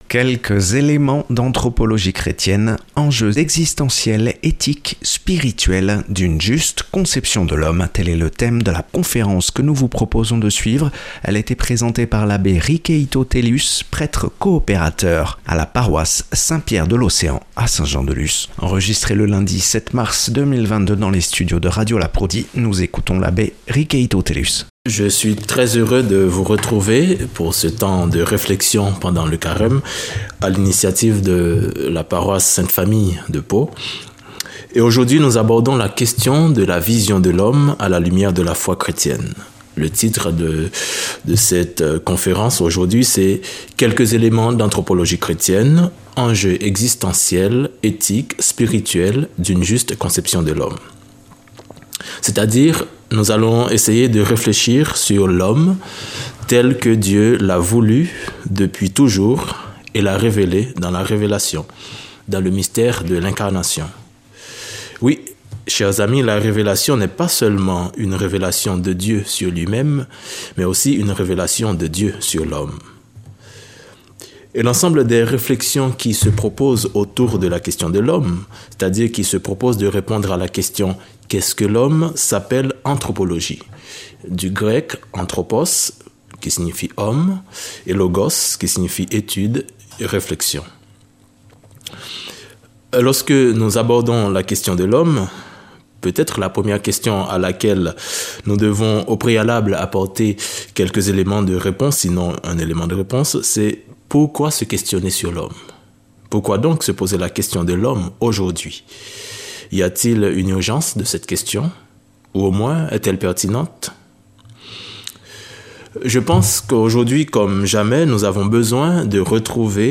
(Enregistré dans nos studios le 07/03/2022).